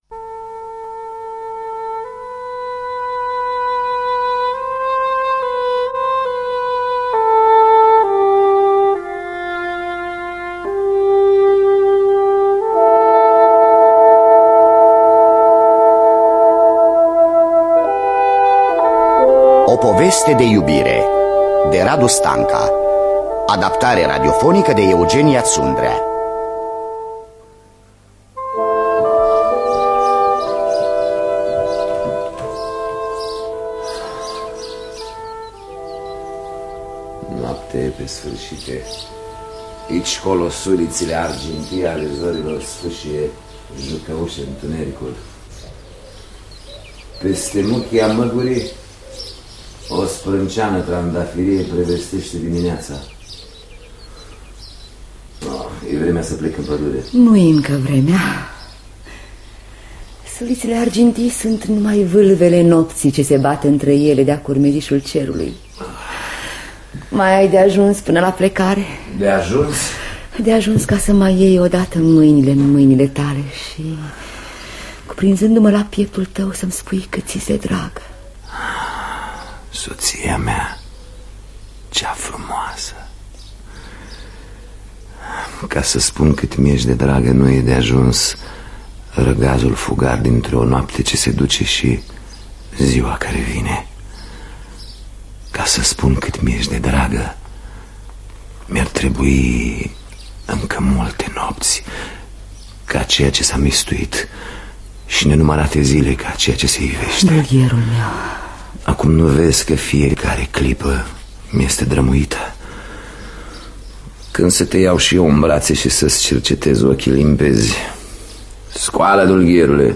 „O poveste de iubire” de Radu Stanca – Teatru Radiofonic Online